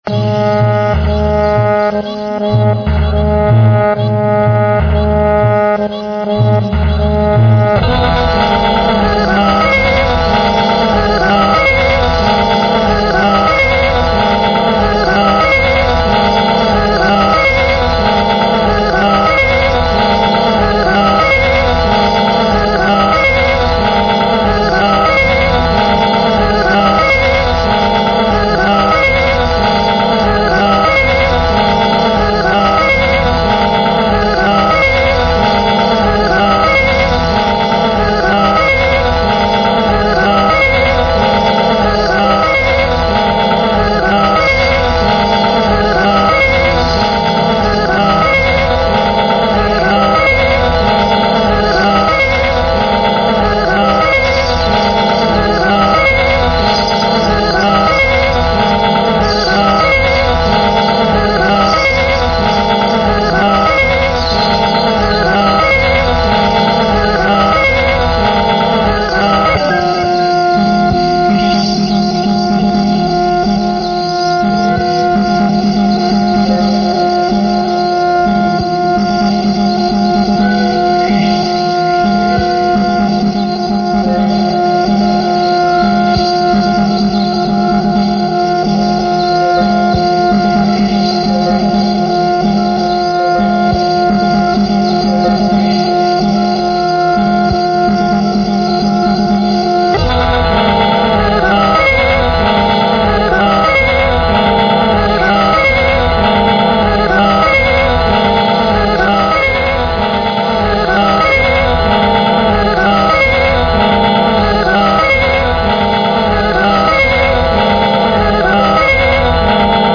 Royalty Free Music for use in any type of